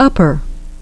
The great majority of two-syllable words in English are stressed on the first syllable, for example, English <)),